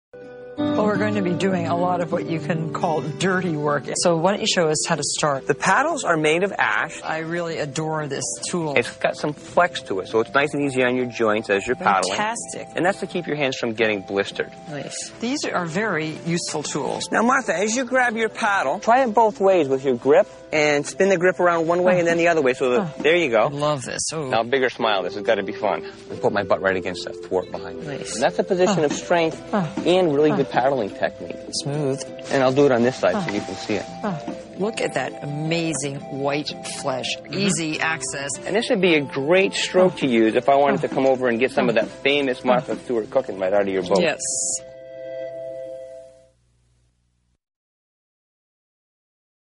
Collage of Celebrity Speech
This is a gallery of collage derived from the utterings of media celebrities, created by artists from around the planet.